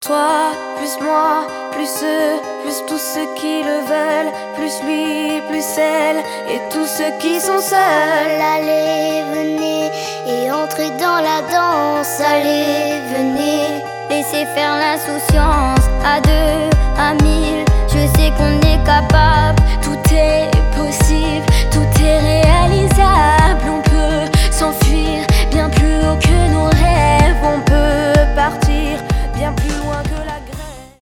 поп
детский голос